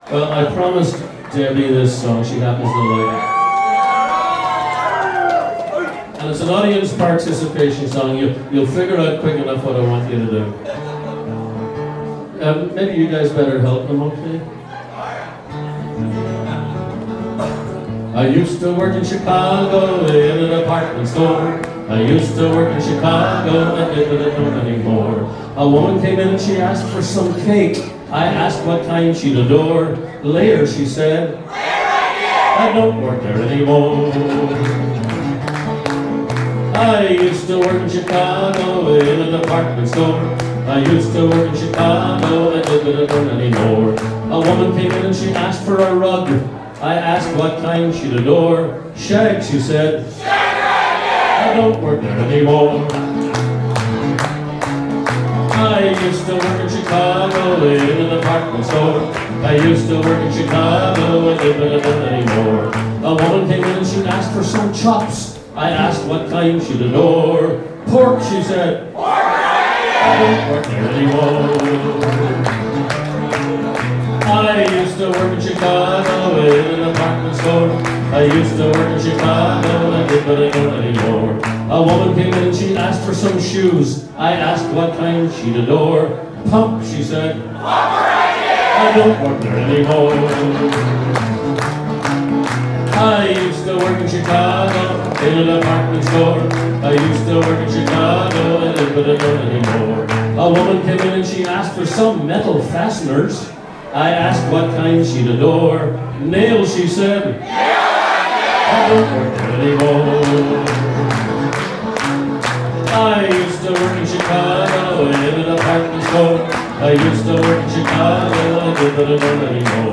Revisiting a performance given by the Irish musician